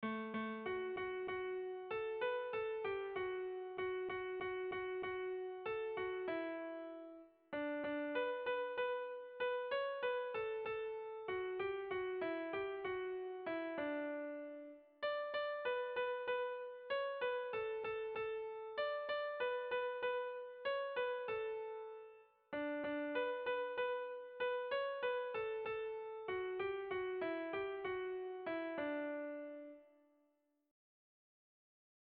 Irrizkoa
Zortziko handia (hg) / Lau puntuko handia (ip)
ABDB